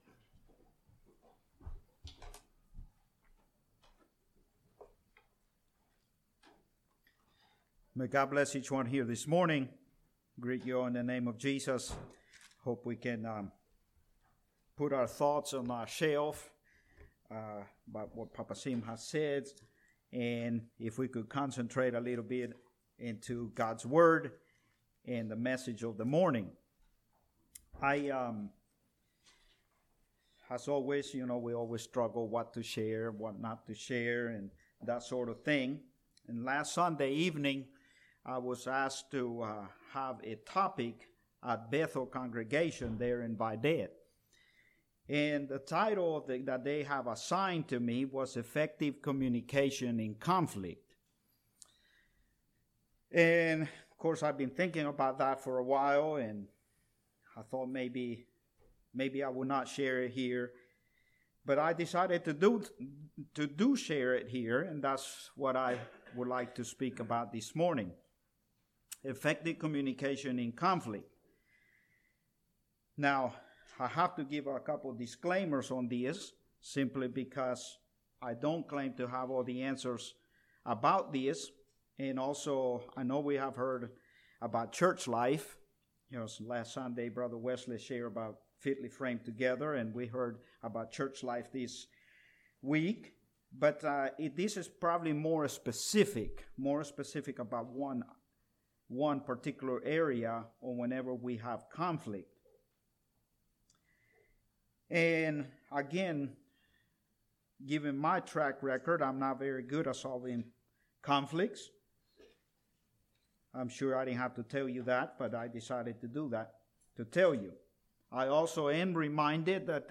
This sermon gives practical teaching on communication.